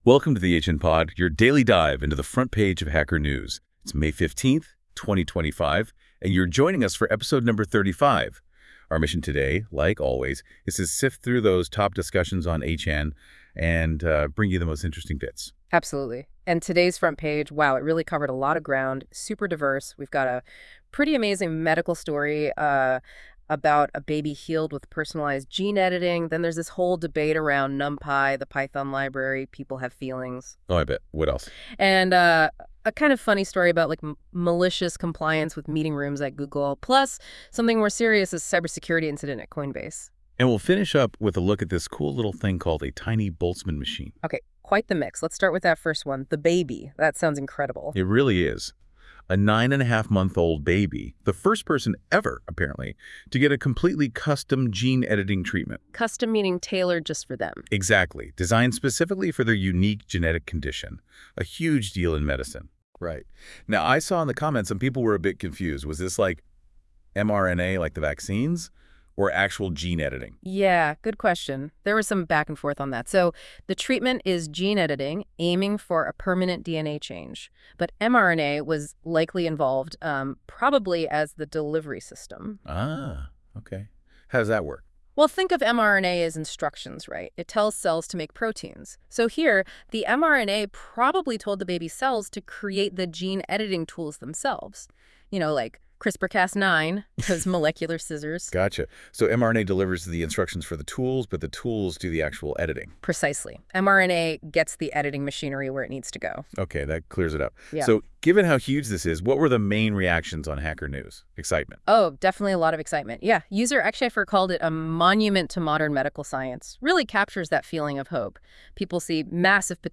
This episode is generated by 🤖 AI.